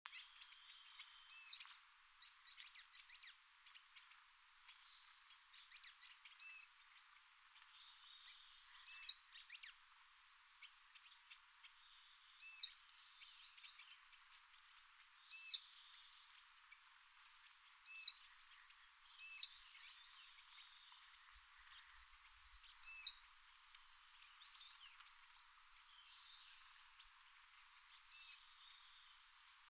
29-5檢查哨2012mar26灰頭花翼1.mp3
紋喉雀鶥 Alcippe cinereiceps formosana
錄音地點 南投縣 信義鄉 塔塔加
錄音環境 草叢
行為描述 鳥叫
錄音: 廠牌 Denon Portable IC Recorder 型號 DN-F20R 收音: 廠牌 Sennheiser 型號 ME 67